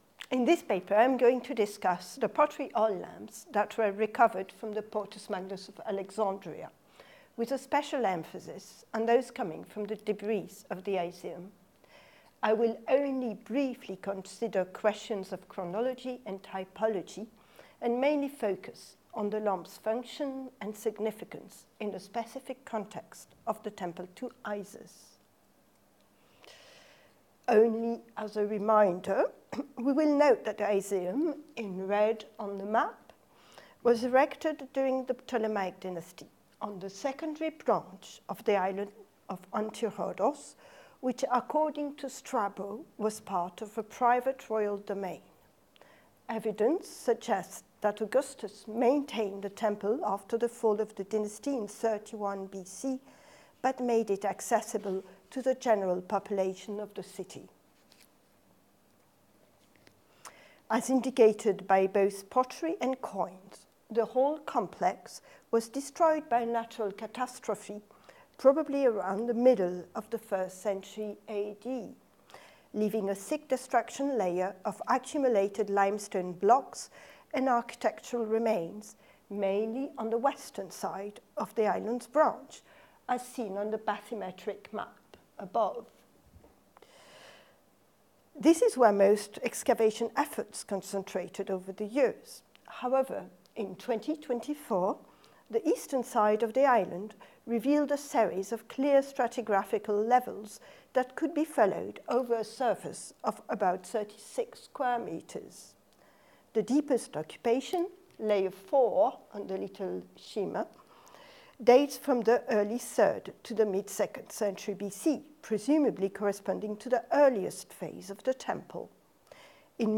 This lecture